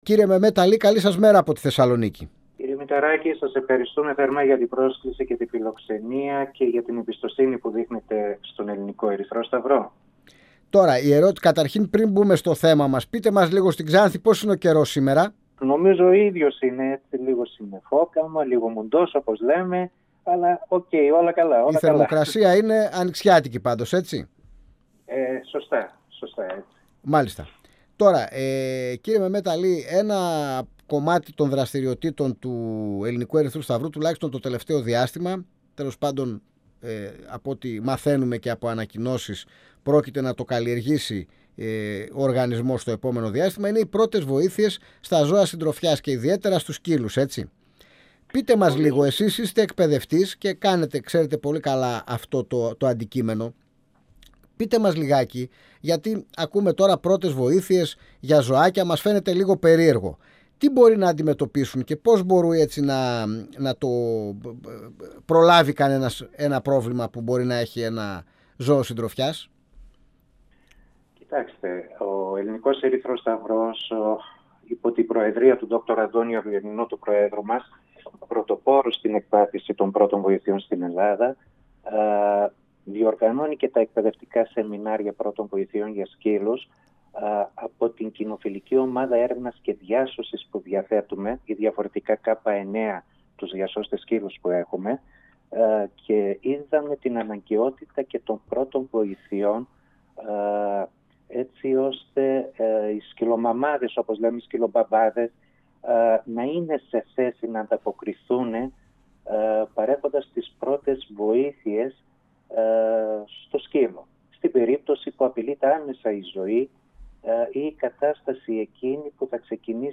Υπάρχουν πάρα πολλές αιτήσεις ενδιαφερομένων.» 102FM Αιθουσα Συνταξης Συνεντεύξεις ΕΡΤ3